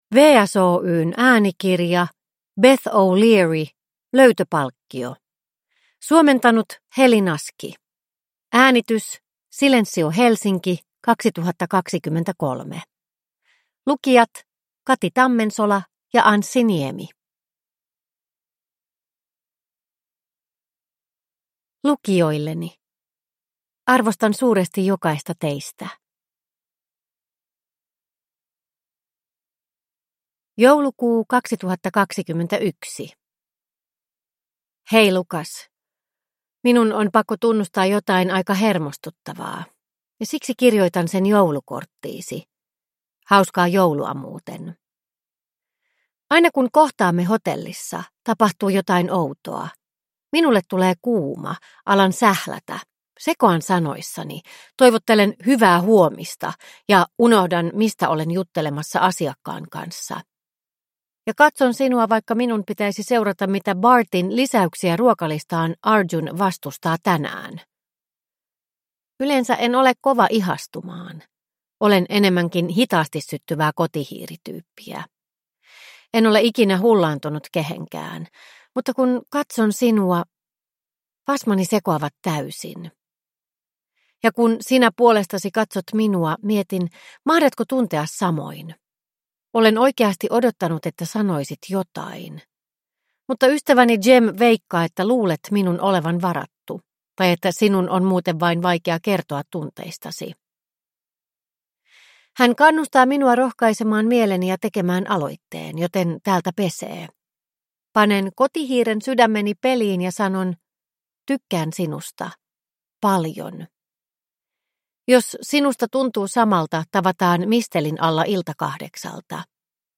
Löytöpalkkio – Ljudbok – Laddas ner